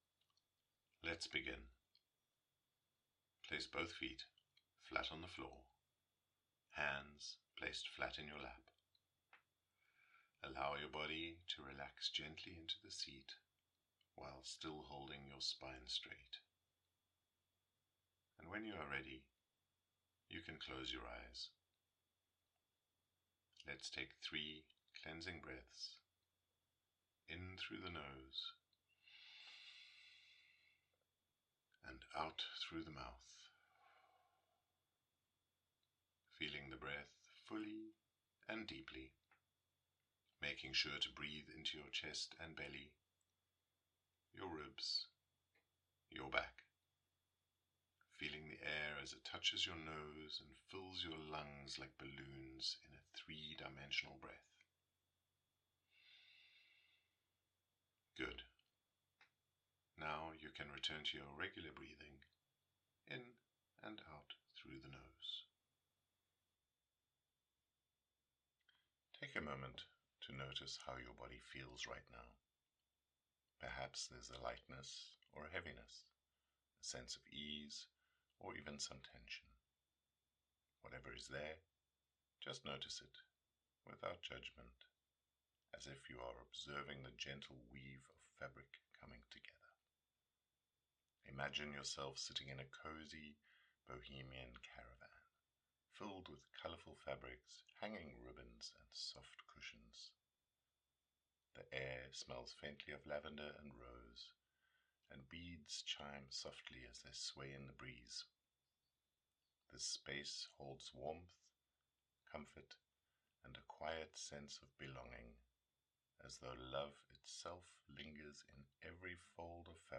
Valentine’s Day Meditation
YE01-meditation.mp3